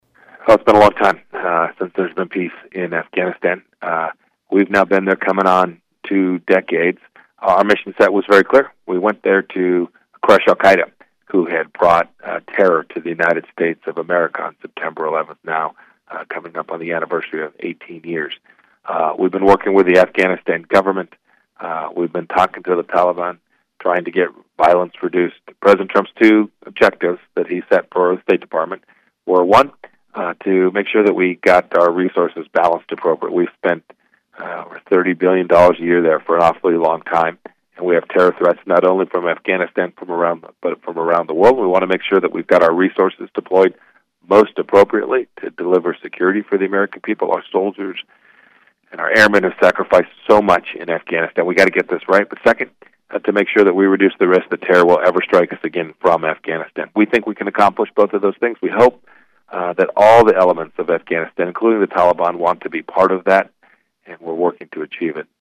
Prior to his Landon Lecture speech Friday, Pompeo joined News Radio KMAN’s morning show and discussed some of his foreign policy achievements since being elevated to the Secretary of State role in April 2018.